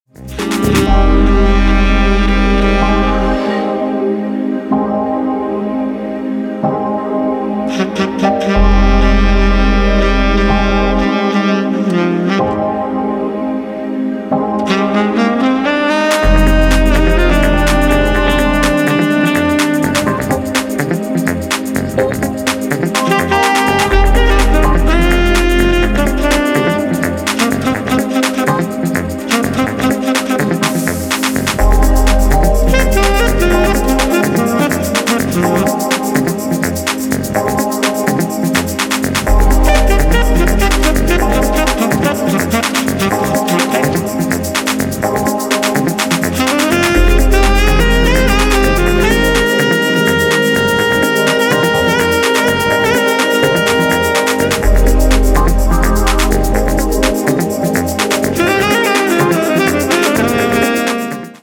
lush keys
timeless electronic music
House Techno Hip Hop